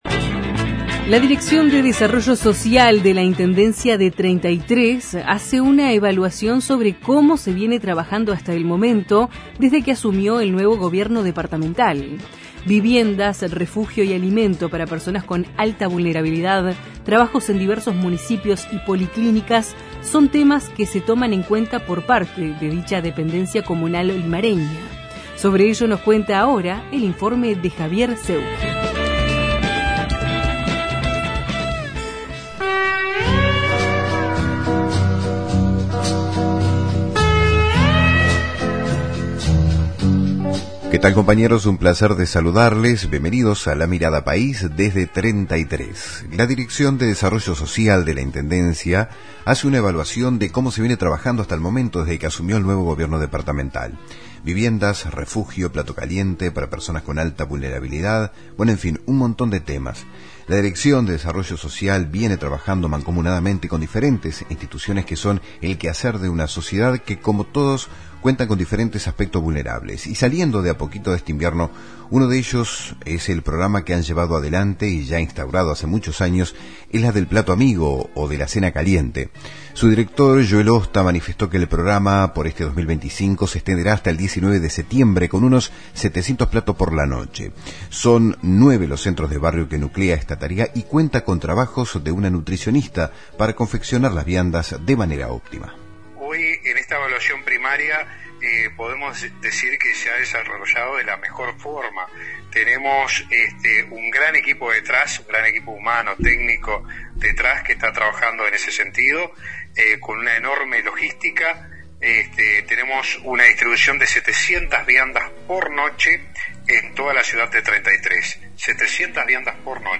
Los informes de nuestros corresponsales en Río Negro, Treinta y Tres y Paysandú.